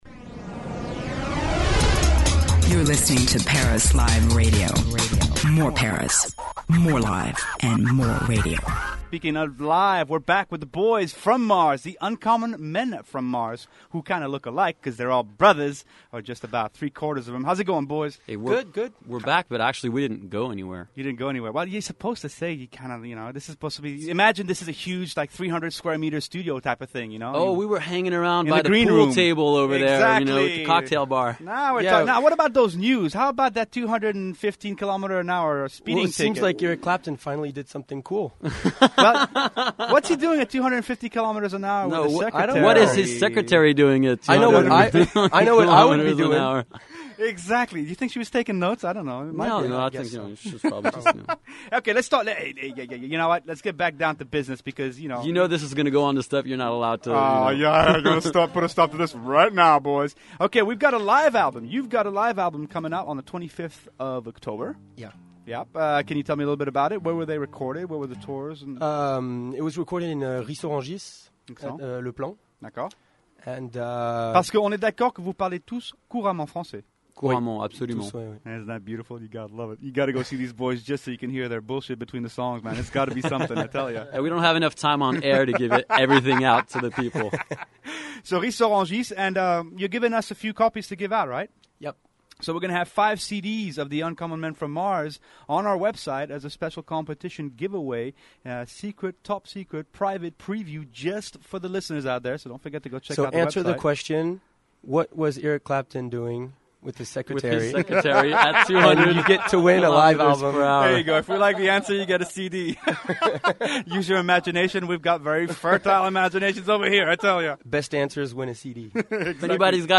punk rock and humour